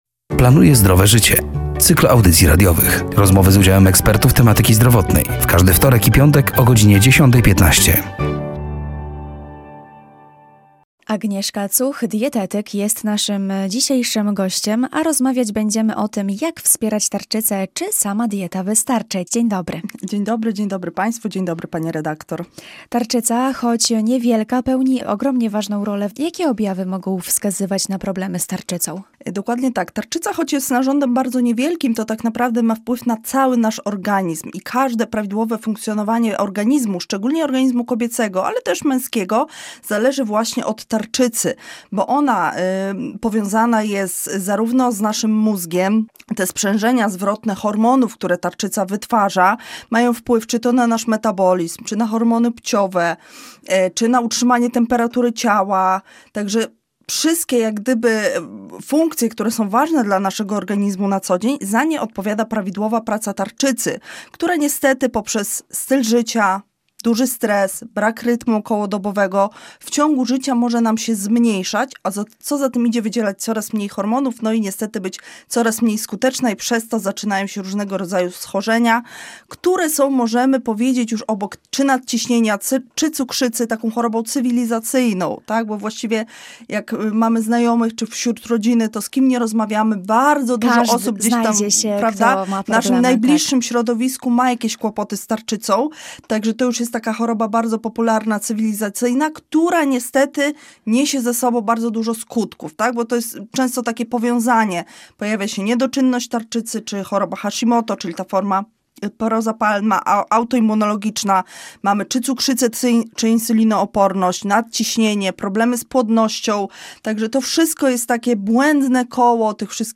,,Planuję Zdrowe Życie”, to cykl audycji radiowych poświęconych upowszechnianiu wiedzy z zakresu zdrowego stylu życia, promujących zdrowie i edukację zdrowotną. Rozmowy z udziałem ekspertów tematyki zdrowotnej.